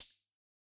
RDM_Raw_MT40-OpHat01.wav